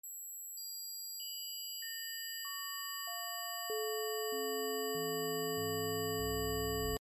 🌀 This is 528 Hz sound effects free download
🌀 This is 528 Hz Golden Ratio Frequency — the sound of abundance. Stay as long as you need. 528 Hz is known as the frequency of love and creation.
It’s 528 plus harmonics spaced using the golden ratio (1.618…) — the same pattern found in seashell spirals, flowers, galaxies, DNA, and even your heartbeat.